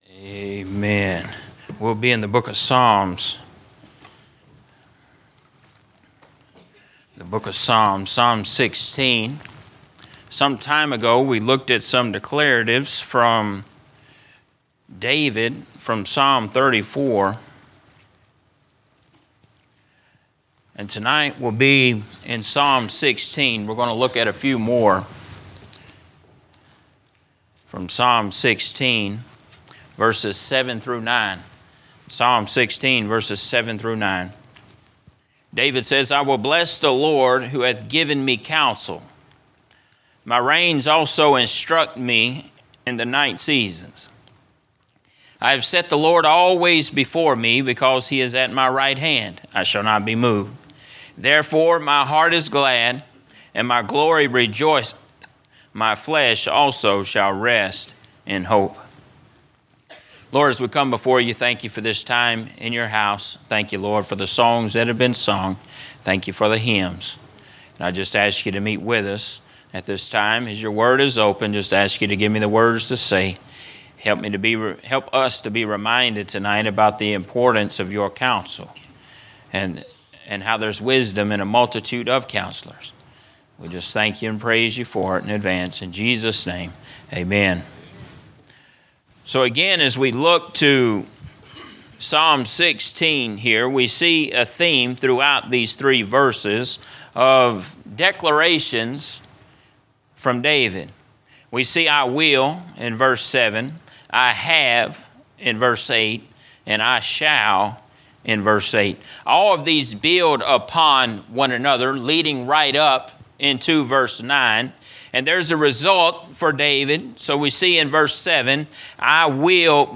From Series: "General Preaching"